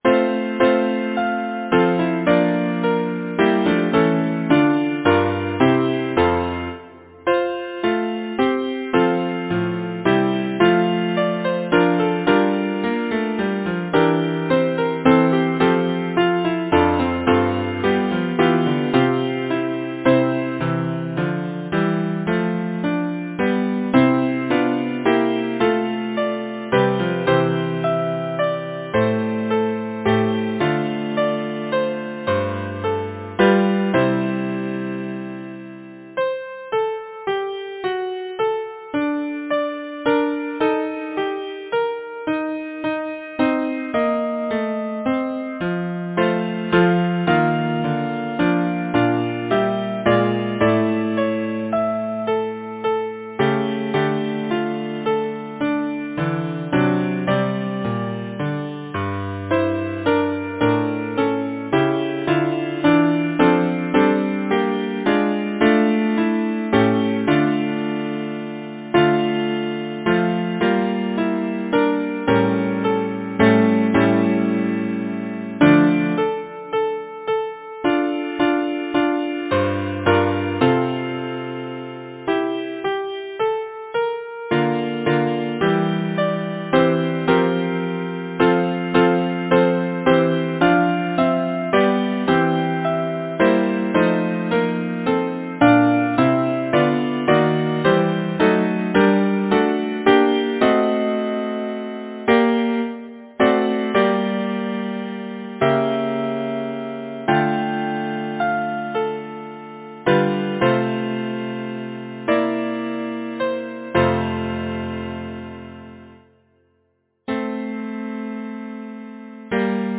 Number of voices: 4vv Voicing: SATB Genre: Secular, Partsong
Language: English Instruments: Keyboard